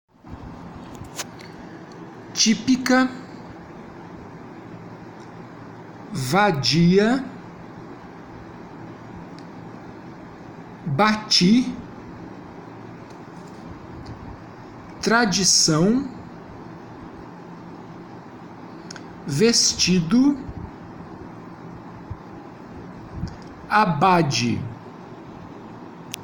Faça download dos arquivos de áudio e ouça a pronúncia das palavras a seguir para transcrevê-las foneticamente.
GRUPO 7 - Africadas - Arquivo de áudio -->